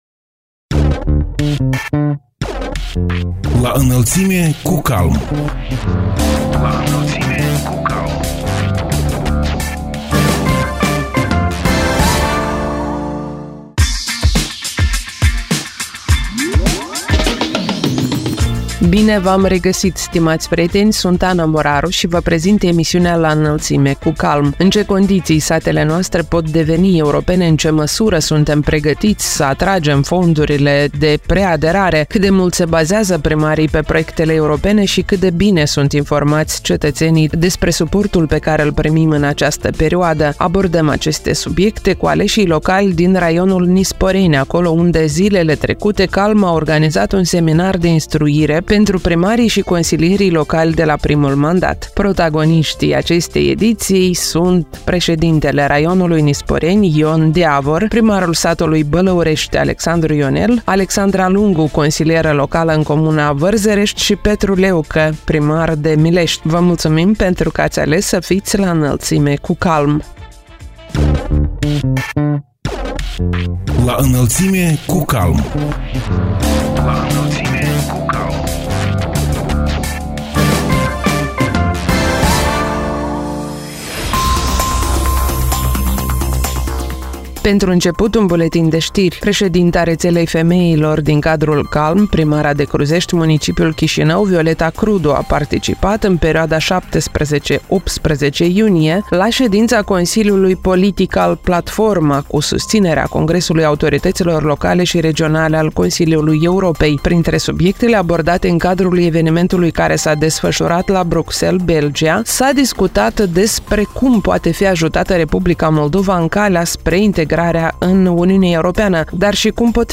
În ce condiții satele noastre pot deveni europene, în ce măsură suntem pregătiți să atragem fondurile de preaderare, cât de mult se bazează primarii pe proiectele europene și cât de bine sunt informați cetățenii despre suportul pe care îl primim în această perioadă? Abordăm aceste subiecte cu aleși locali din raionul Nisporeni, acolo unde zilele trecute CALM a organizat un seminar de instruire pentru primarii și consilierii locali de la primul mandat. Protagoniștii emisiunii „La Înălțime cu CALM” sunt președintele raionului Nisporeni, Ion Diavor; primarul satului Bălăurești, Alexandru Ionel; consiliera locală în comuna Vărzărești, Alexandra Lungu și primarul de Milești, Petru Leucă.